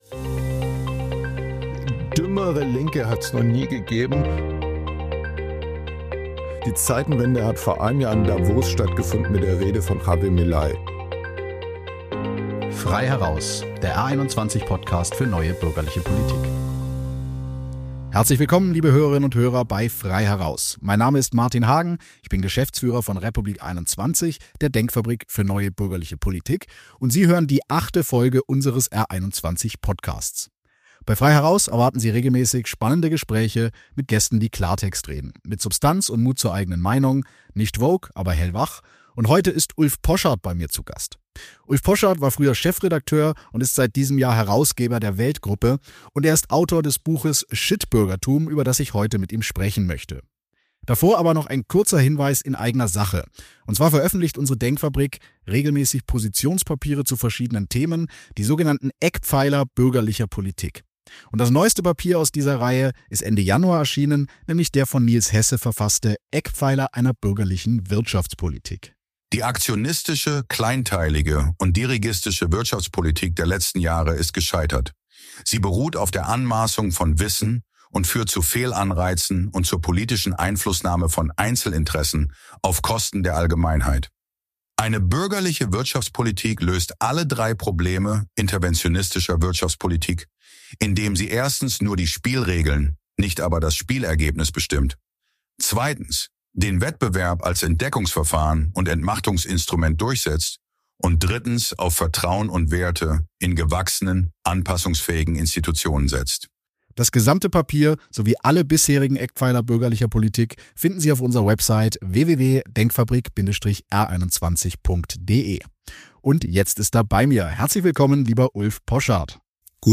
Der Journalist und Autor spricht mit R21-Geschäftsführer Martin Hagen über sein neues Buch „Shitbürgertum“.